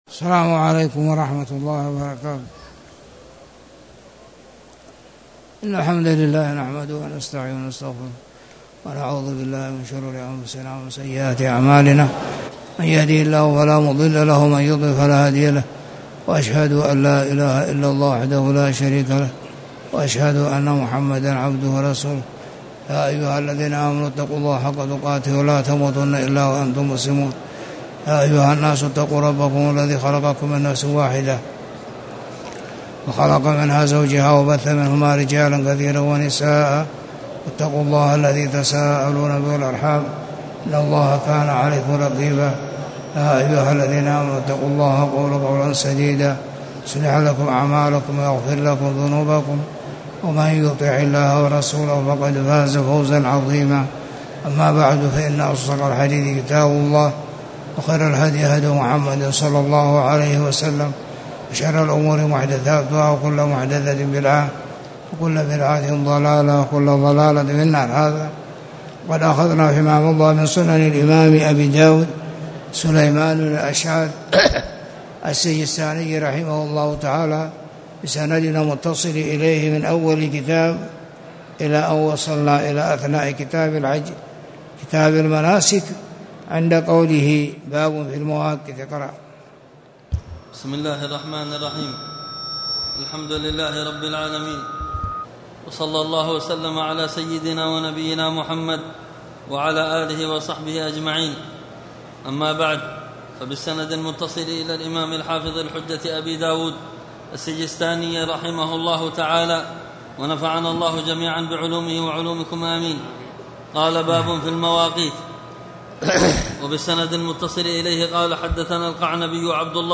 تاريخ النشر ٢٦ شوال ١٤٣٨ هـ المكان: المسجد الحرام الشيخ